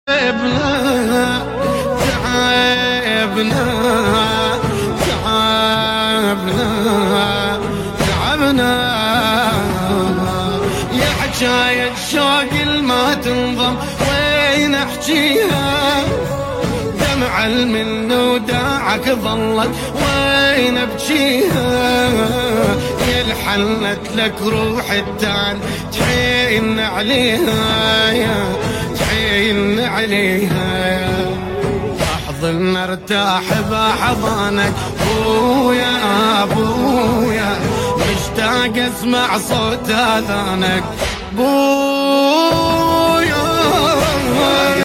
mercedes 190e v8 sound effects